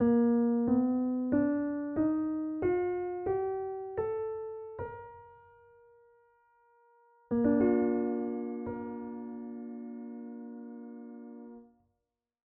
MOS-Skala 3L4s in 10EDO
10edo_3L4s.mp3